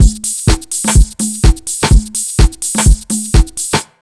126BEAT1 2-R.wav